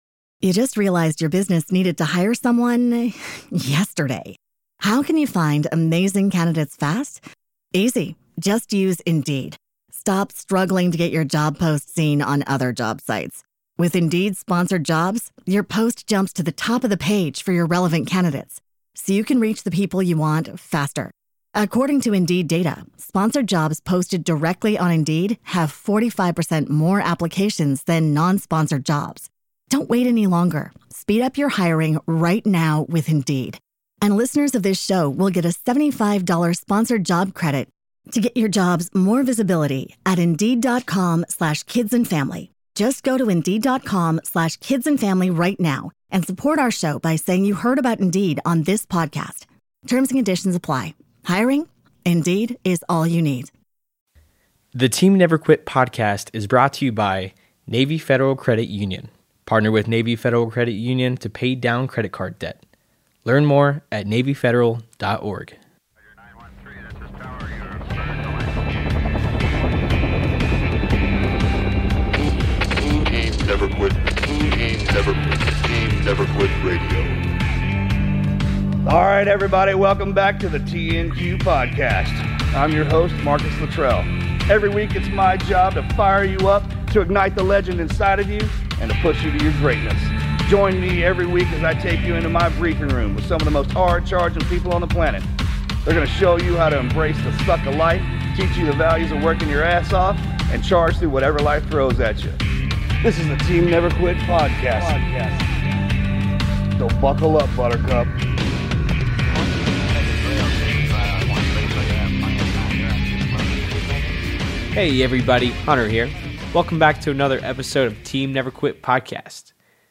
recap the 2022 episodes. It serves as a capsule-form reminder of episodes you may be interested in listening to that you might have missed.